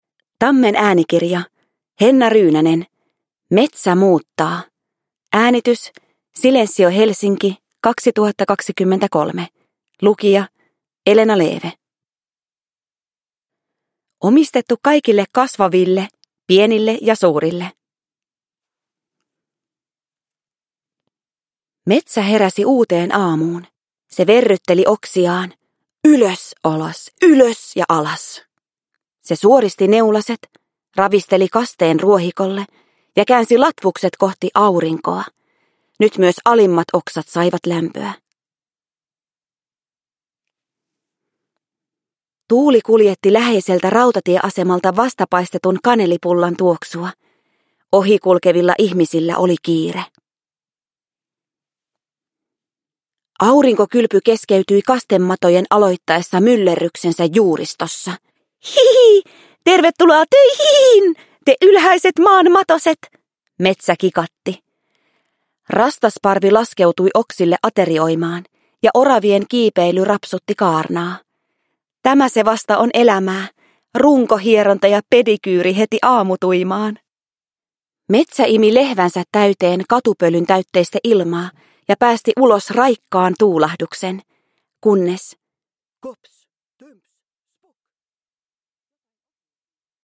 Metsä muuttaa – Ljudbok – Laddas ner
Uppläsare: Elena Leeve